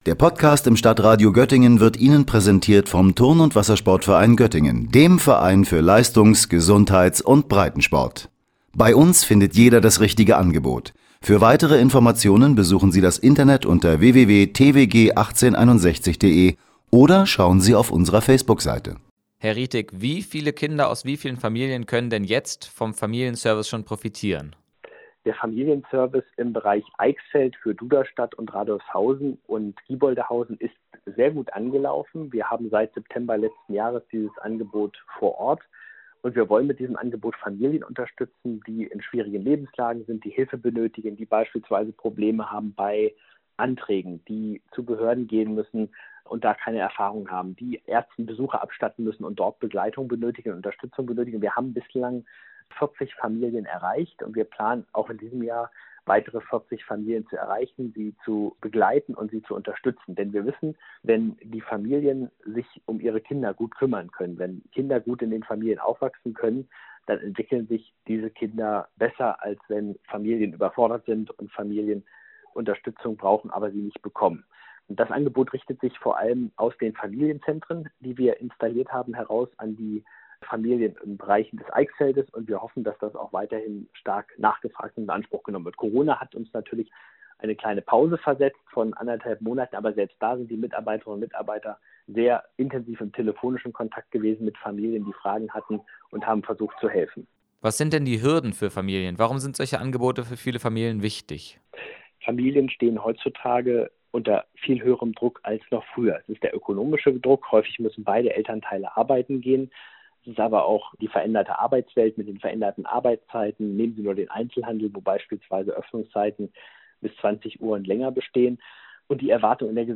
Er ist im Landkreis Göttingen Kreisrat für Jugend, Bildung, Arbeit, Soziales und Kultur.